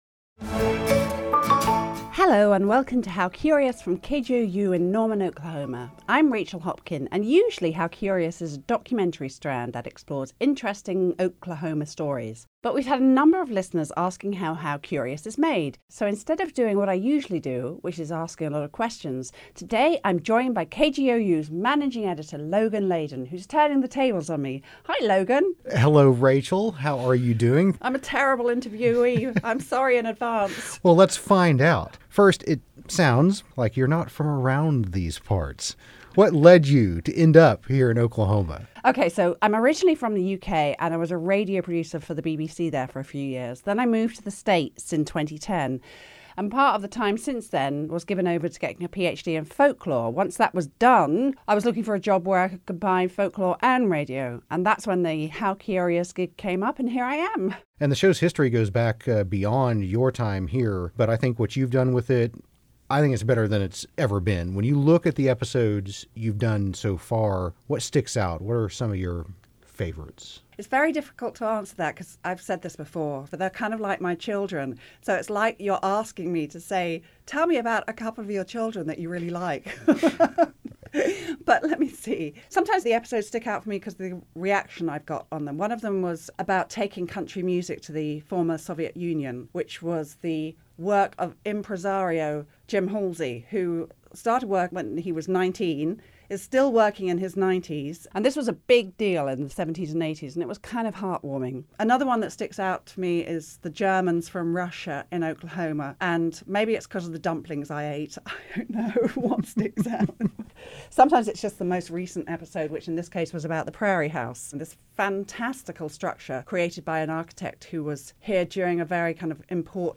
how-curious-june-convo-pod-with-tag.mp3